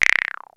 VARIOUS FILT 1.wav